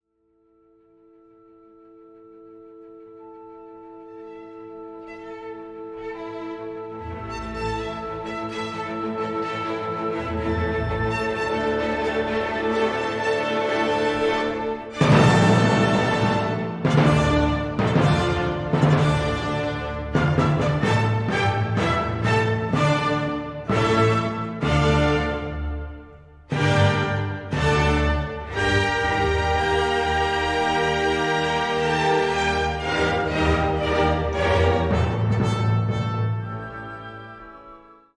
I hear in this a lot of sweat of the brow. This is all about getting things done by brute force.
By the way, the snippets of audio we’ve been listening to are from that 1972 recording of the Chicago Symphony Orchestra and Chorus, Georg Solti conducting, recorded at the Krannert Center of Performing Arts on the campus of the University of Illinois.